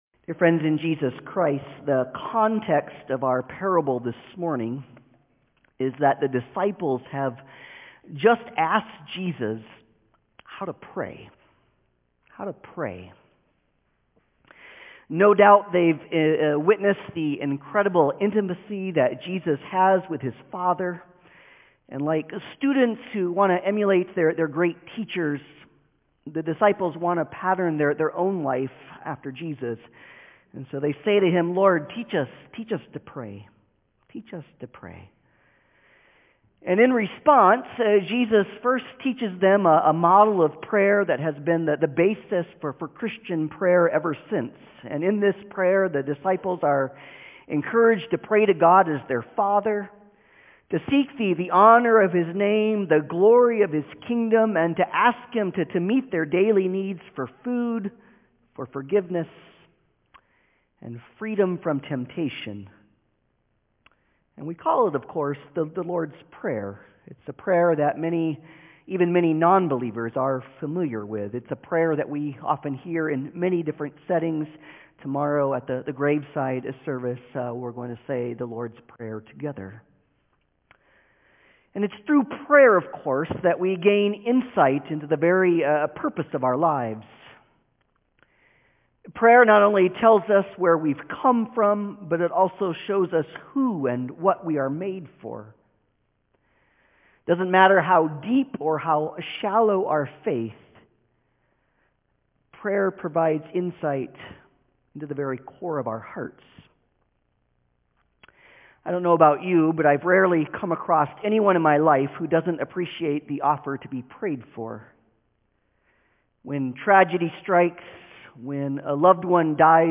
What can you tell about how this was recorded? Passage: Luke 11:5-13 Service Type: Sunday Service